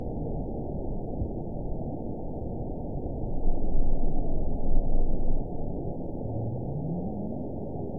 event 920970 date 04/21/24 time 00:14:47 GMT (1 year, 1 month ago) score 9.48 location TSS-AB05 detected by nrw target species NRW annotations +NRW Spectrogram: Frequency (kHz) vs. Time (s) audio not available .wav